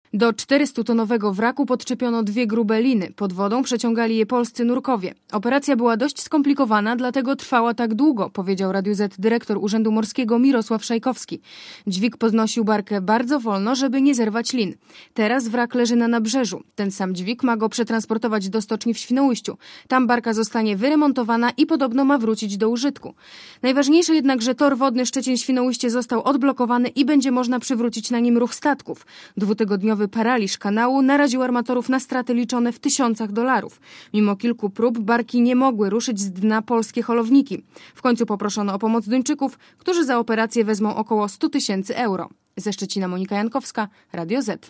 Relacja reportera Radia Zet (350Kb)Komentarz audio